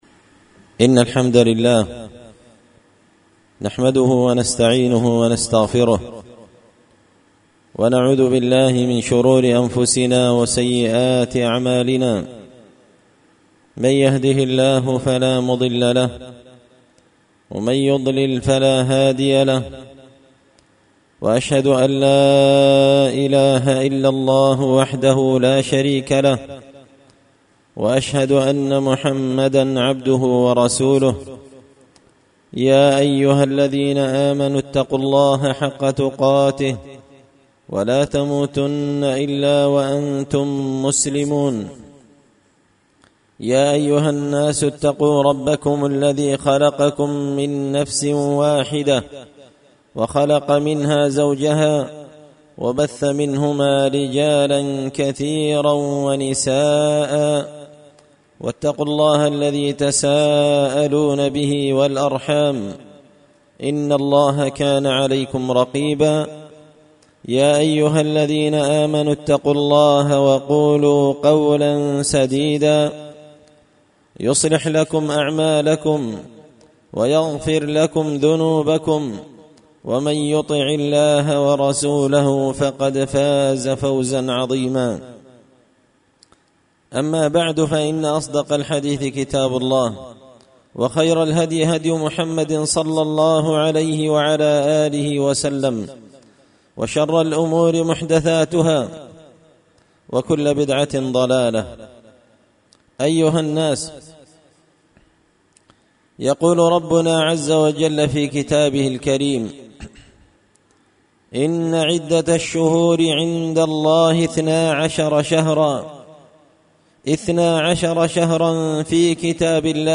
خطبة جمعة بعنوان – عبر وعظات من قصة موسى عليه الصلاة والسلام
دار الحديث بمسجد الفرقان ـ قشن ـ المهرة ـ اليمن